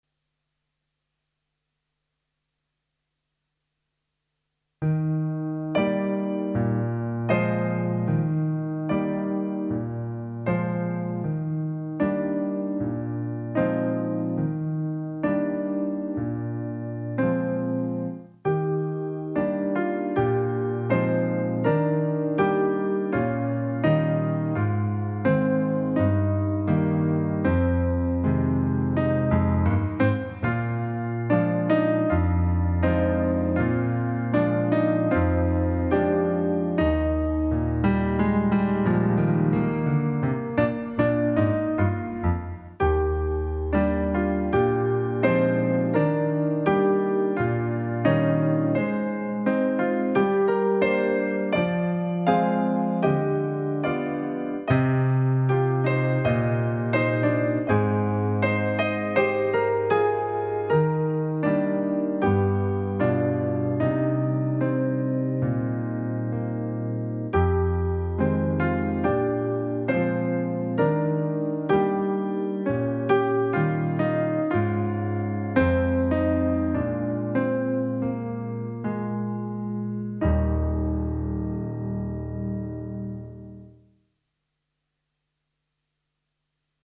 minus Vocal